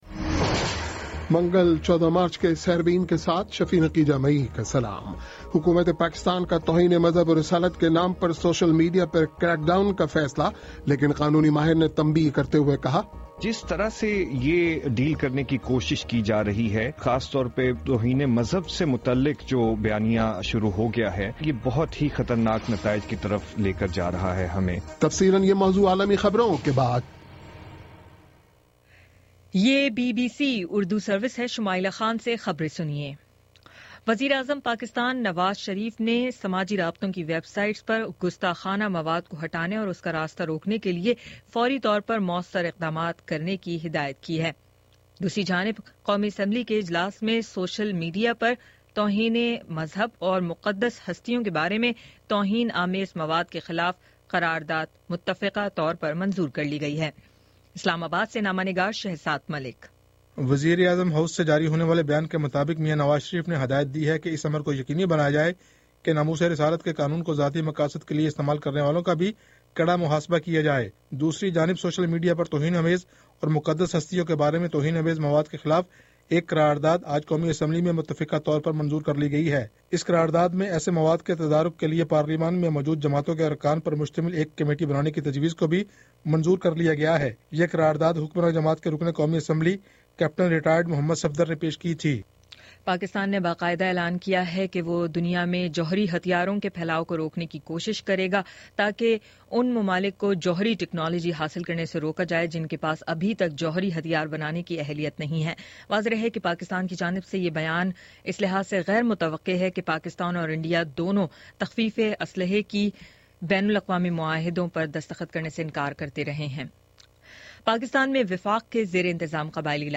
منگل 14 مارچ کا سیربین ریڈیو پروگرام